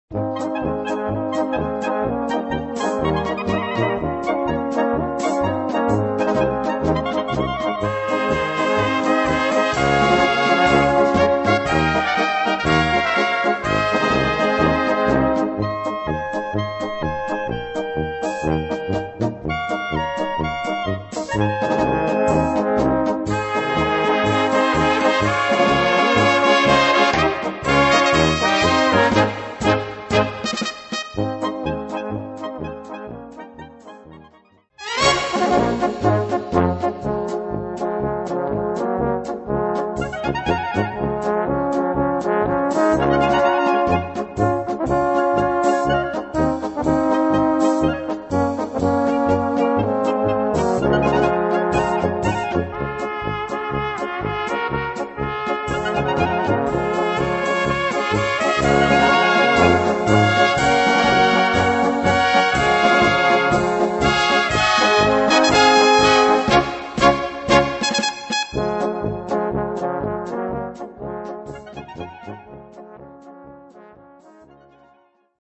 Gattung: Polka
Besetzung: Blasorchester